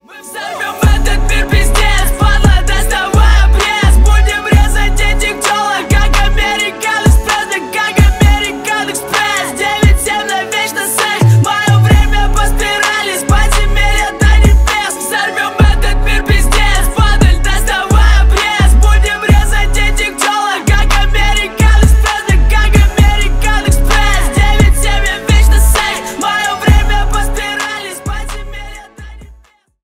Рэп и Хип Хоп
злые